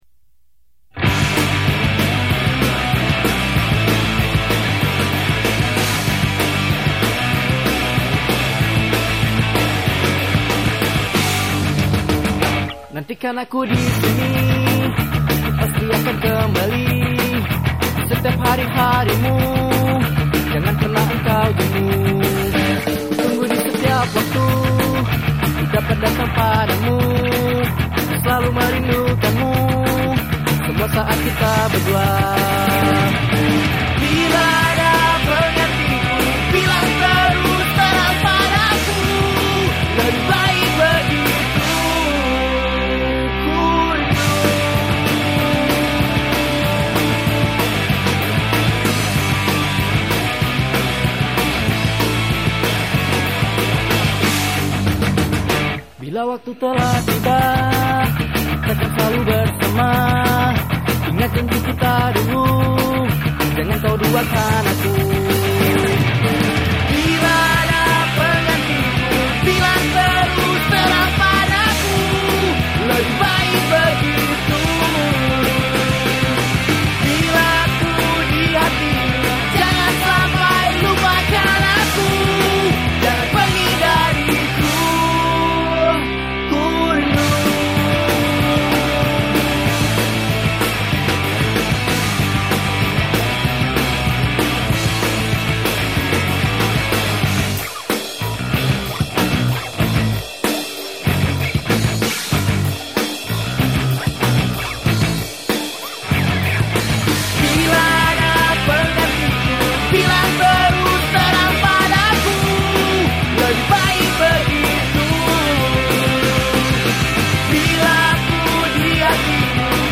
Lagu bergenre pop yang bervareasikan rock
drum
gitar